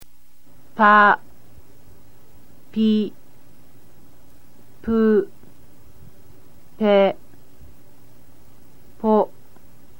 The H* sound is much harder than the western H sound.